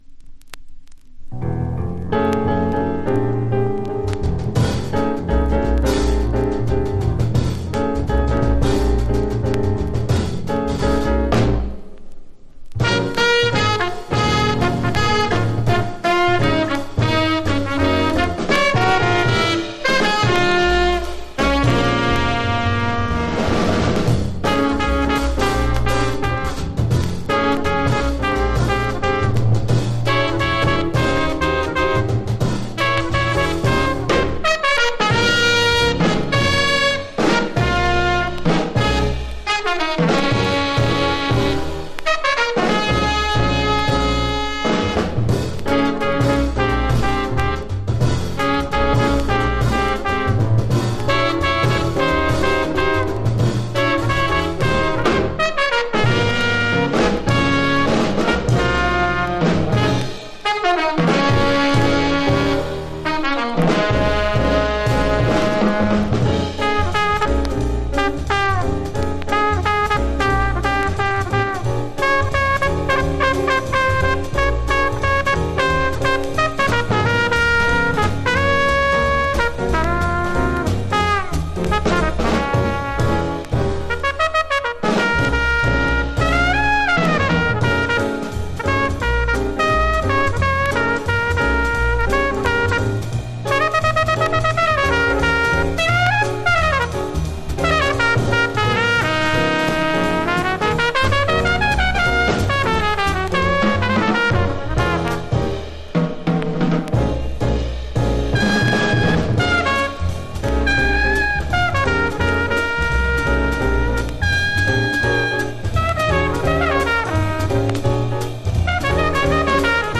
（小傷によりチリ、プチ音ある曲あり）
Genre US JAZZ